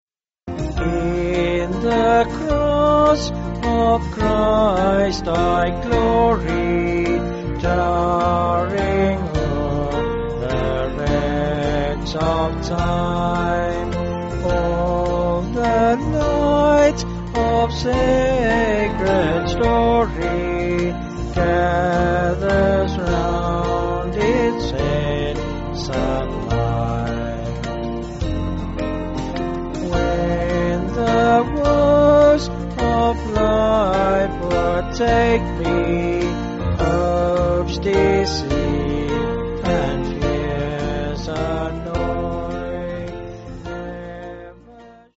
4/Bb-B
Vocals and Band